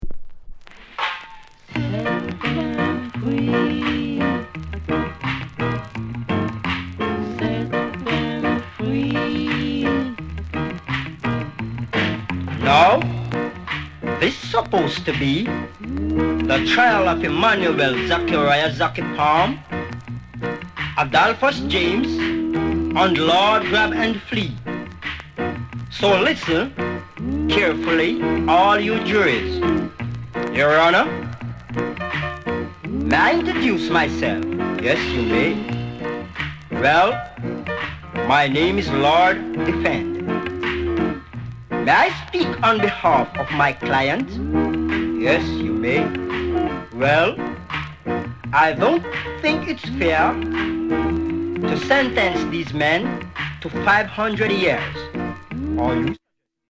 RARE ROCKSTEADY